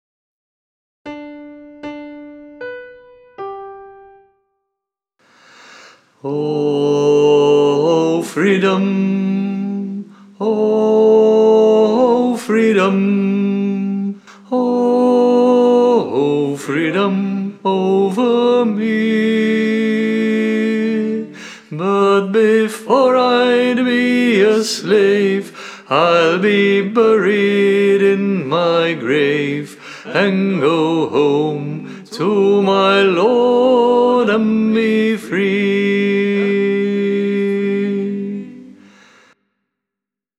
Everybody sing Freedom - sop.m4a